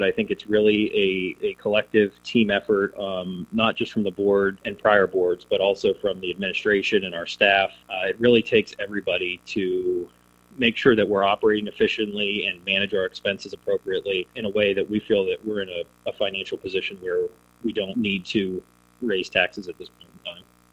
Board president Eric Matava said that this year marked the 9th year United has gone without a tax increase, and he credited a team effort with continuing that streak.